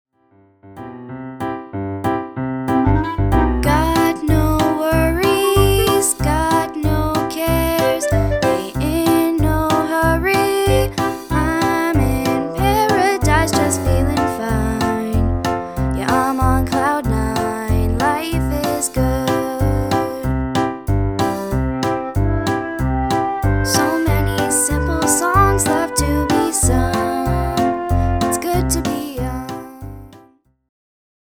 designed for young voices